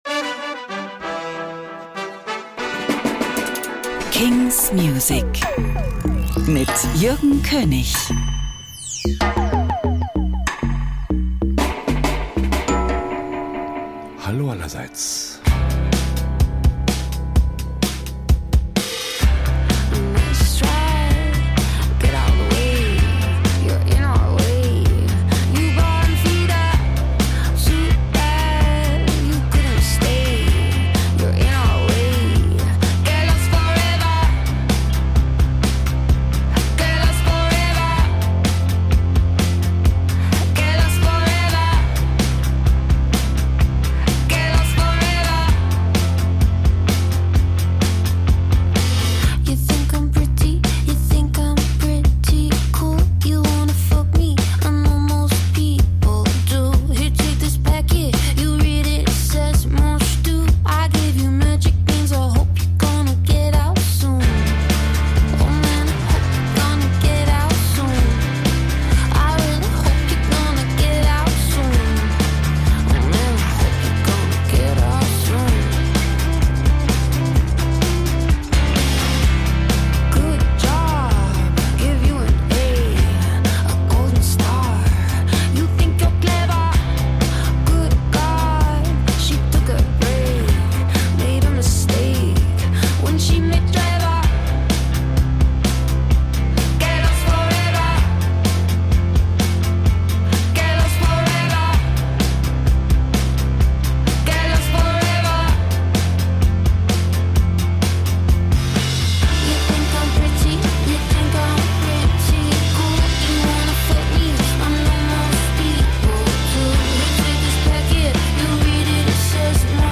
indie & alternative releases